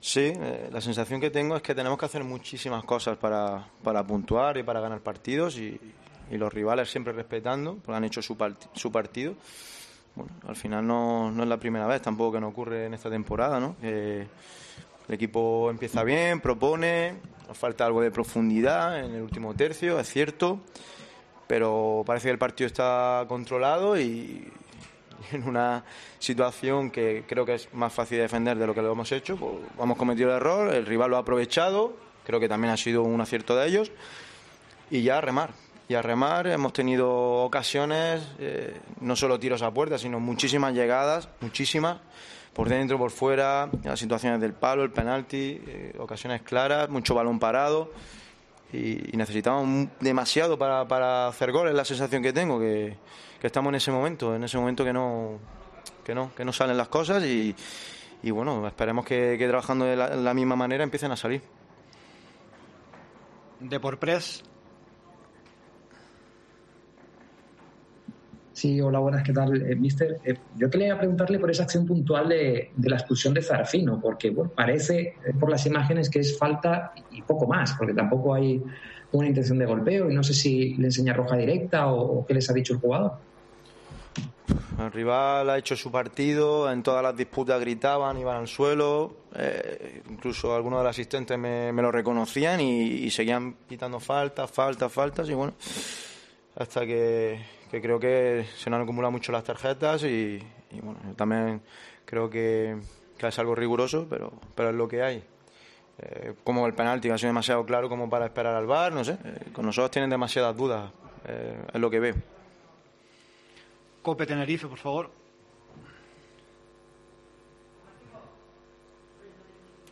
POSTPARTIDO